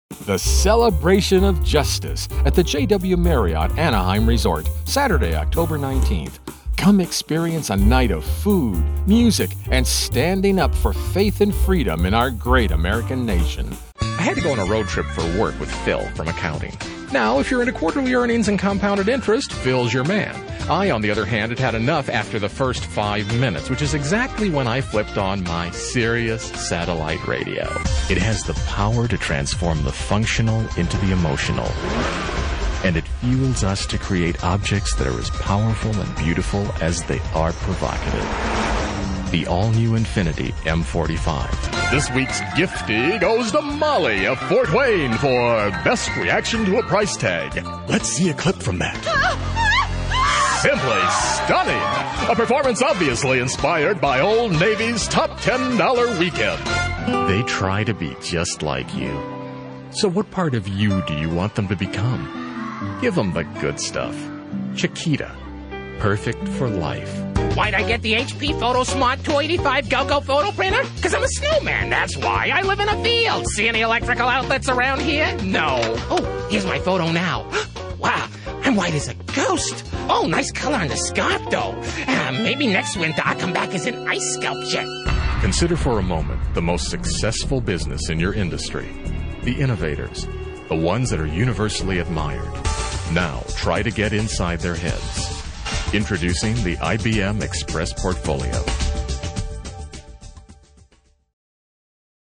Commercial English Demo